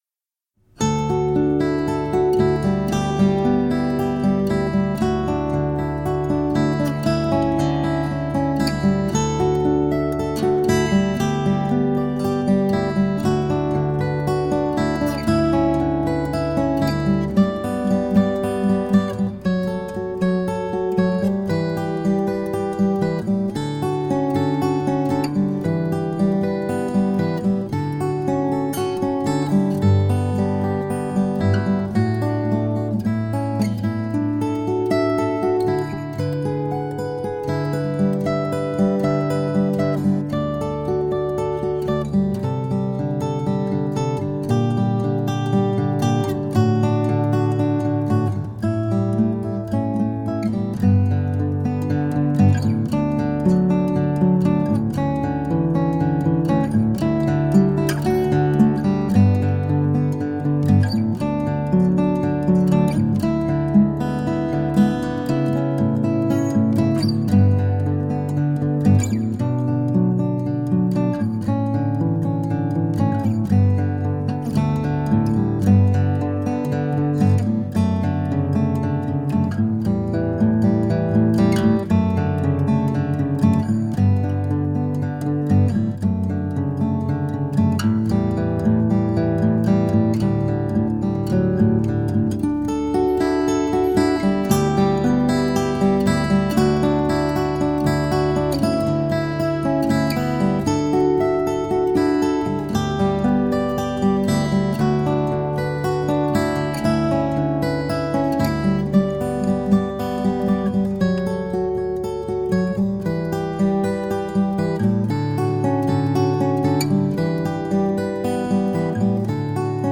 GUITAR INSTRUMENTAL EVERY SEASON
For some reason, she tuned the guitar differently to find a certain, poignant sound.
With every minor chord she could feel her heart ache.
guitar-instrumental-every-season-brings-you-back.mp3